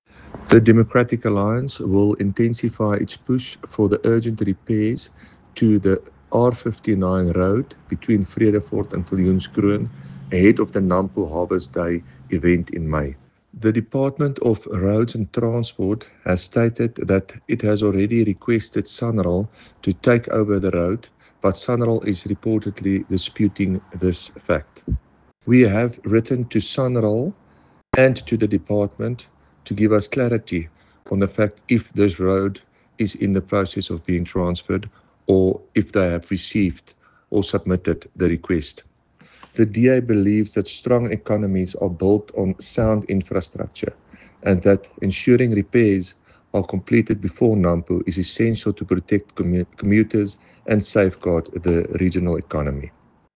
English and Afrikaans soundbites by David van Vuuren MPL, and